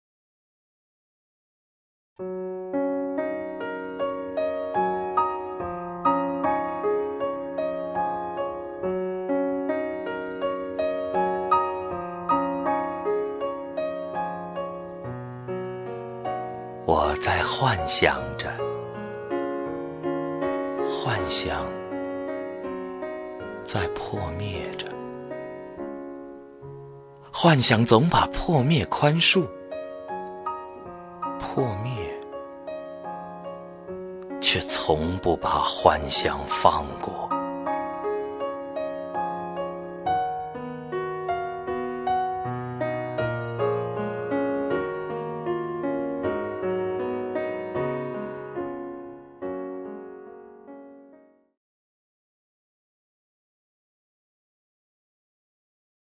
赵屹鸥朗诵：《我的幻想》(顾城) 顾城 名家朗诵欣赏赵屹鸥 语文PLUS